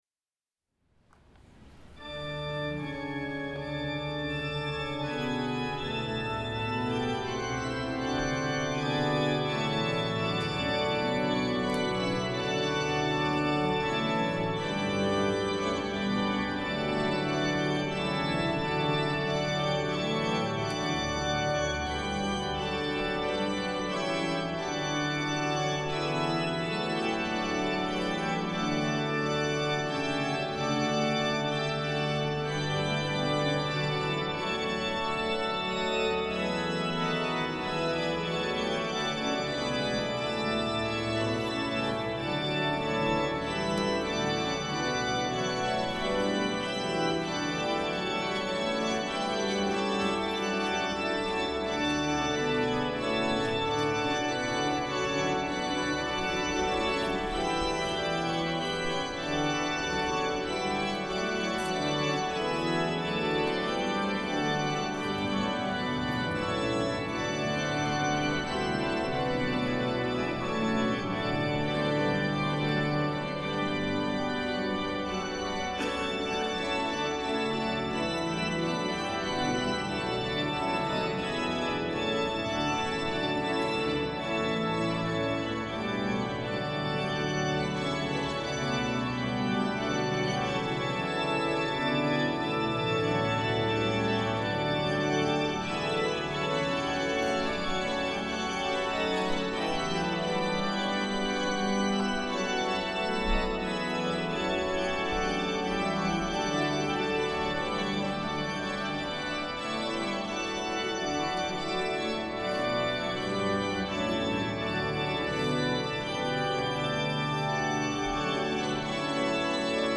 Órgano de la epístola de la catedral de Granada (Leonardo Fernández Davila, 1744-1746).
Grabación en directo (2011)
tiento-pangelingua-cabanilles.mp3